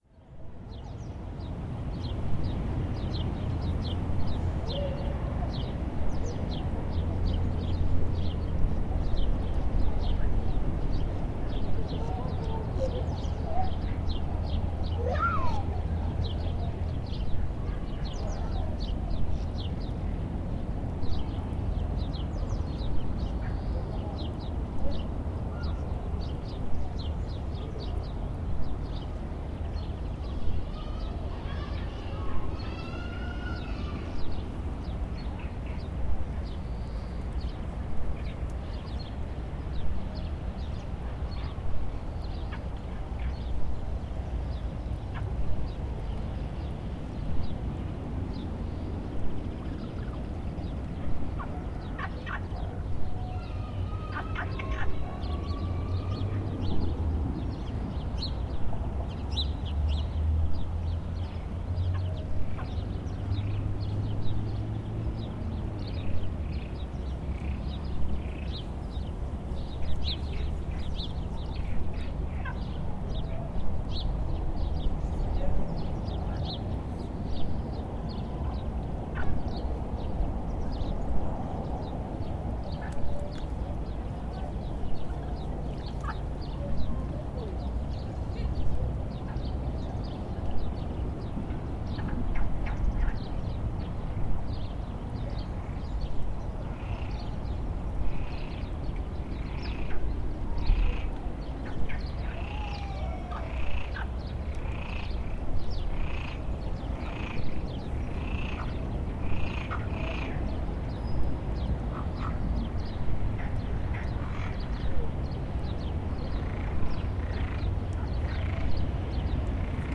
NODAR.00088 – Viseu: Rio Pavia- Rãs, pássaros e carros ao longe
Gravação do som das rãs à superfície do Rio Paiva. Gravado comZoom H4.
Tipo de Prática: Paisagem Sonora Rural
Viseu-Rio-Pavia-Rãs-pássaros-e-carros-ao-longe.mp3